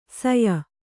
♪ saya